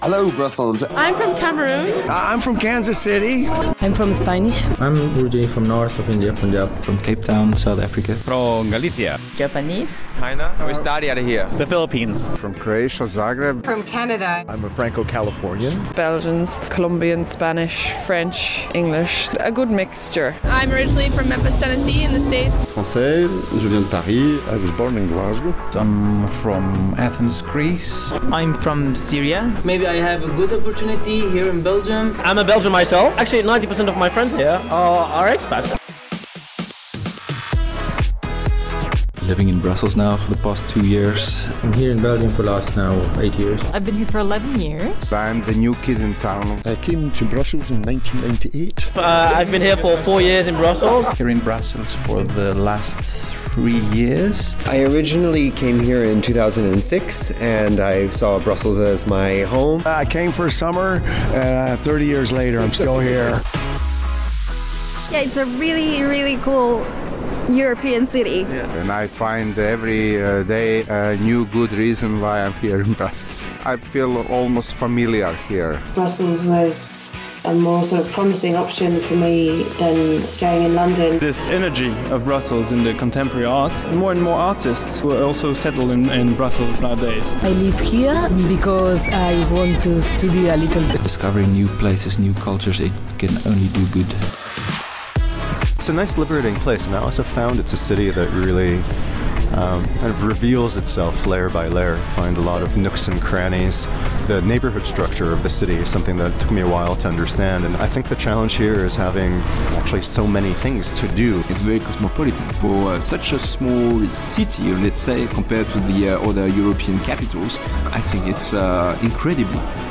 Here's a bit of an audio mosaic from our first 3 months of expat reports